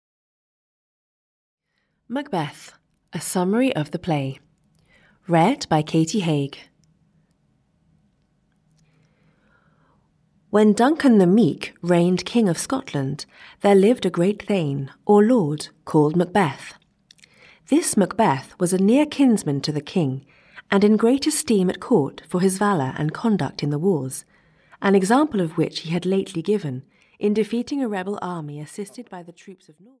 Macbeth, a Summary of the Play (EN) audiokniha
Ukázka z knihy